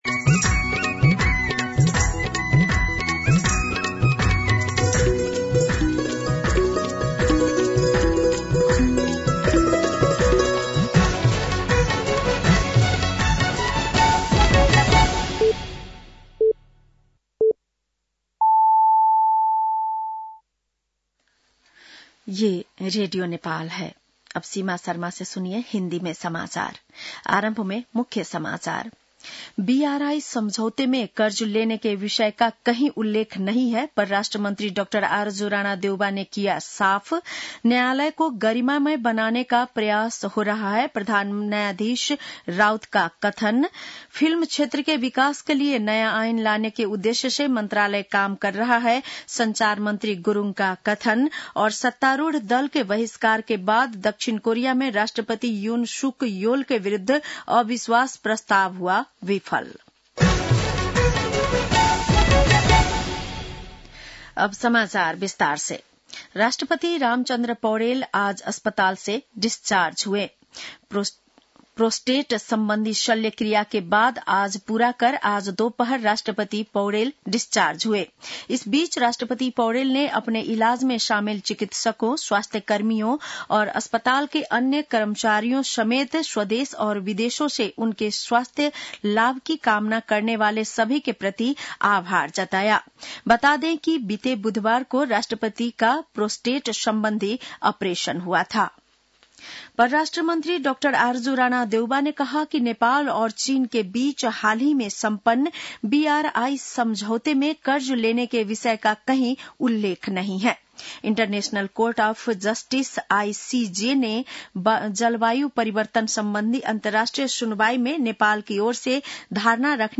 बेलुकी १० बजेको हिन्दी समाचार : २३ मंसिर , २०८१
10-PM-Hindi-News-8-22.mp3